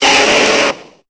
Cri de Reptincel dans Pokémon Épée et Bouclier.